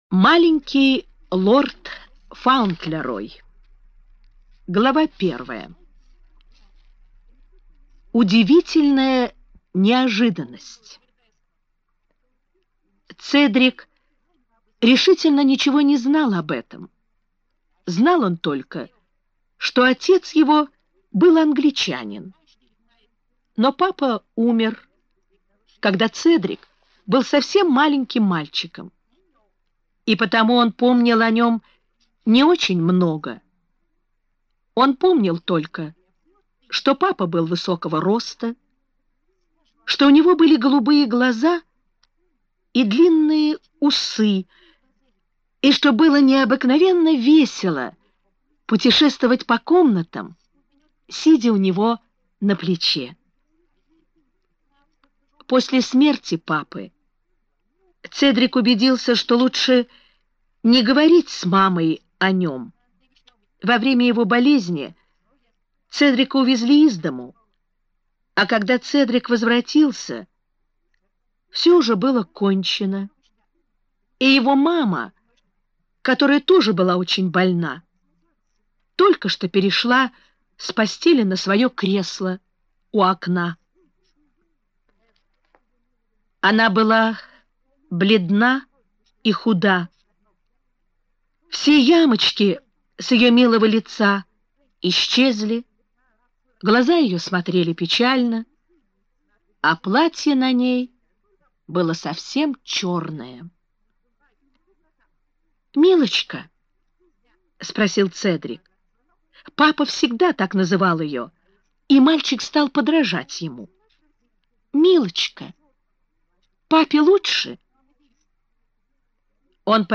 Маленький лорд Фаунтлерой - аудио роман Бёрнетт - слушать онлайн